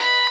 guitar_004.ogg